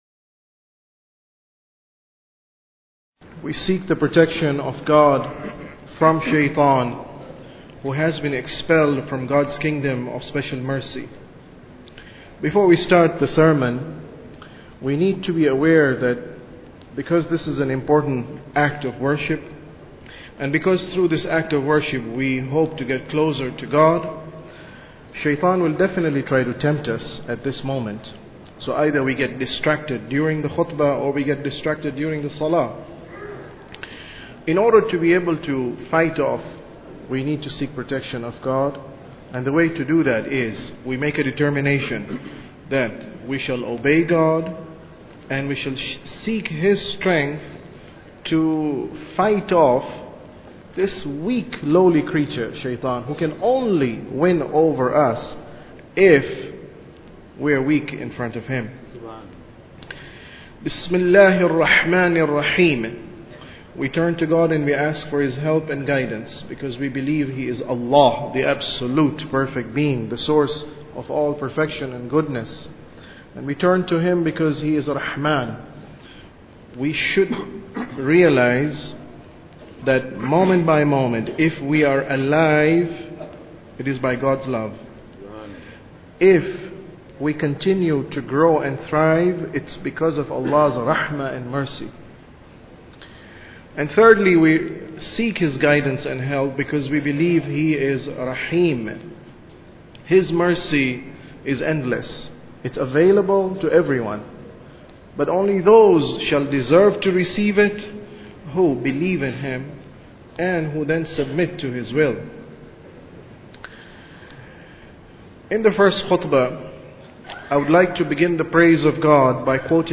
Sermon About Tawheed 13